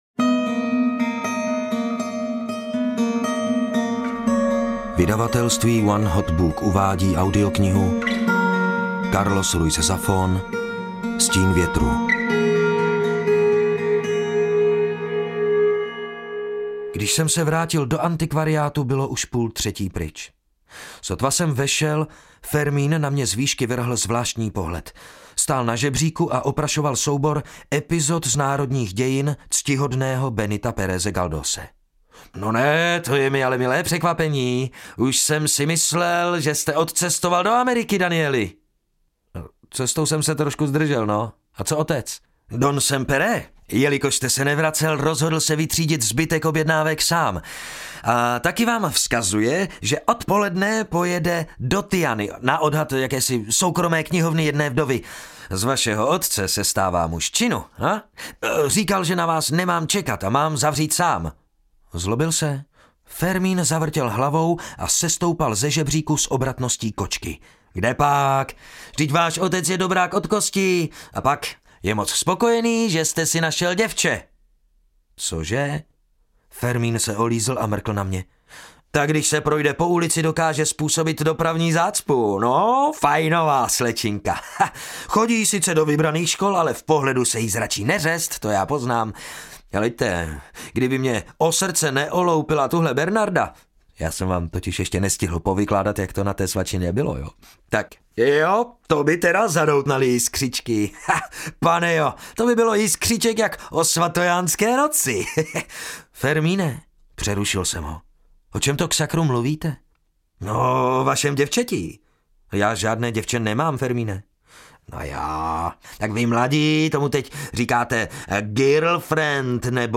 Stín větru audiokniha
Ukázka z knihy